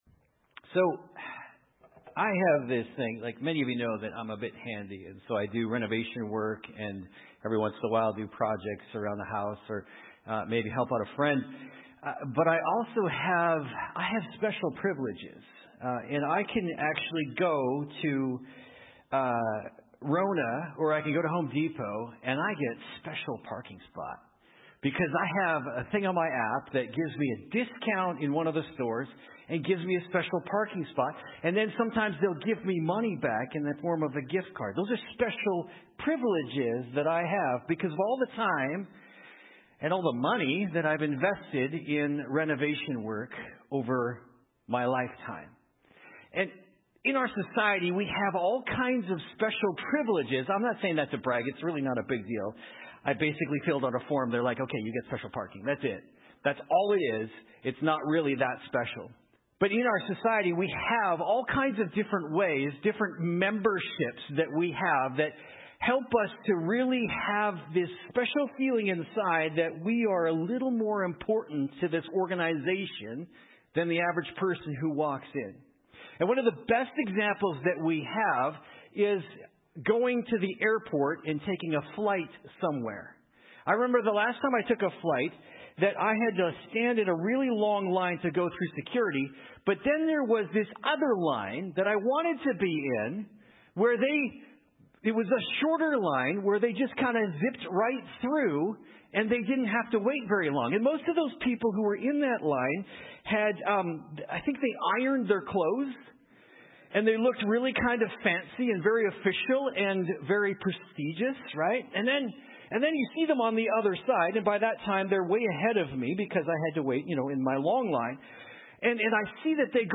Sep 28, 2025 Even Ground MP3 Notes Sermons in this Series The Struggle With Prayer How Long Is This Going To Take?